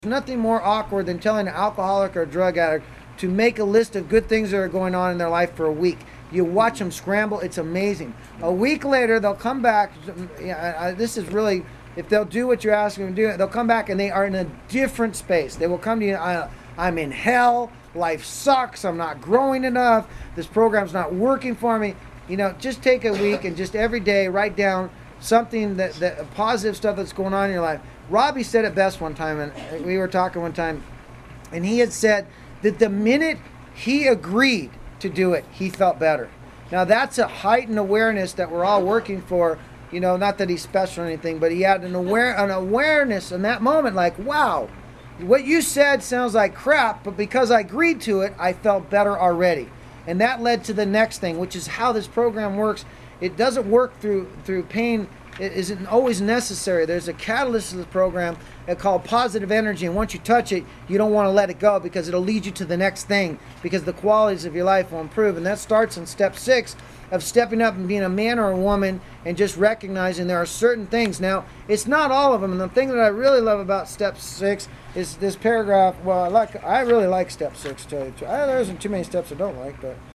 This audio archive is a compilation of many years of lecturing. The spiritual and science used by Thought Life Connection were borrowed from religion, science, and medicine.
Within the lectures, you will hear people ask questions about why am I where I am, how can I get to a better place and what is blocking me.